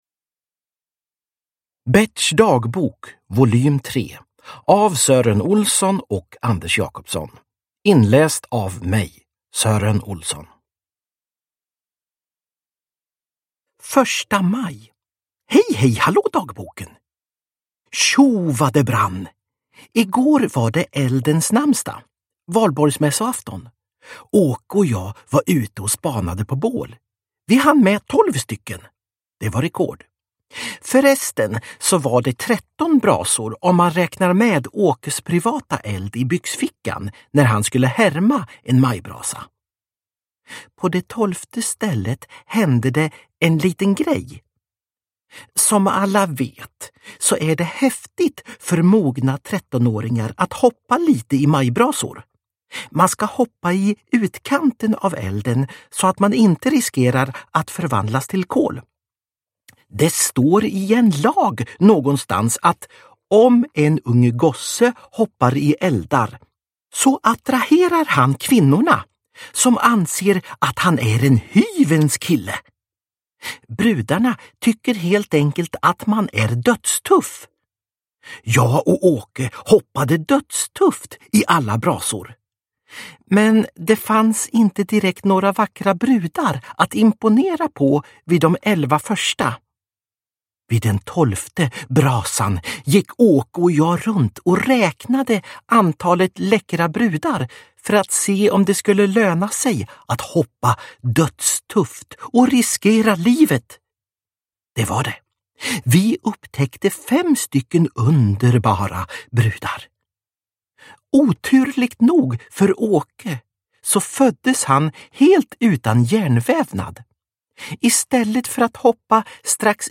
Berts dagbok 3 – Ljudbok – Laddas ner
Uppläsare: Sören Olsson